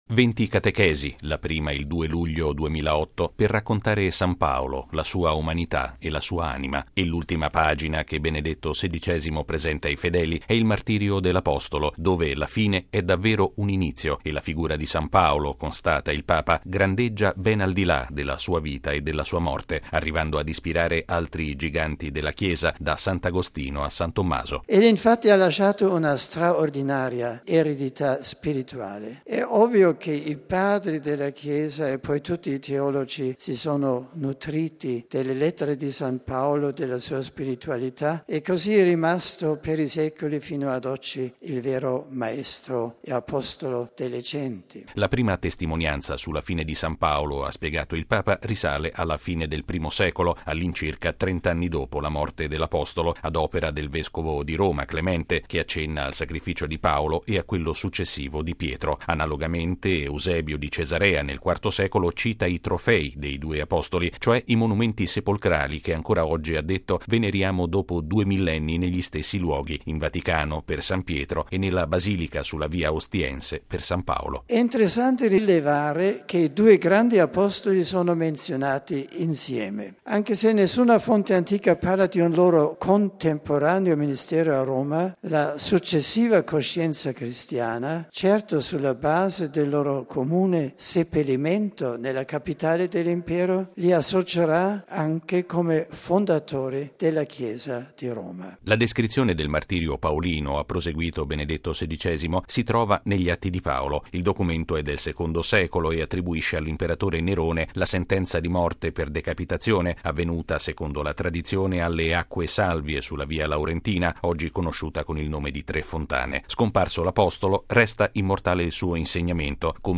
Benedetto XVI ha concluso con queste considerazioni l’ultima udienza generale dedicata all’Apostolo delle Genti.